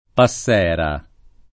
passera [p#SSera] s. f. — lo stesso che passero, sia femmina, sia in genere; e anche nome (seguìto da un agg. o altra specificaz.) di certi uccelli, perlopiù dei passeracei, come pure di un pesce (la passera di mare o p. assol.); per tacere d’altri usi metaforici — sim. il cogn. Passera (in qualche famiglia, però, alterato in